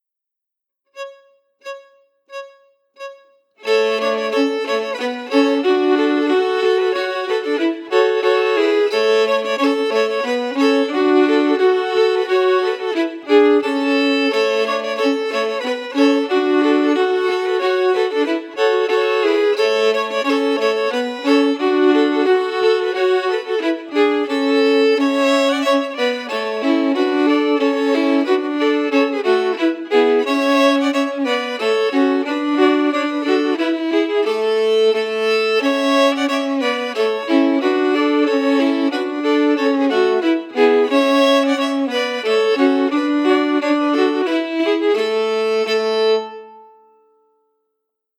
Key: A
Form: Reel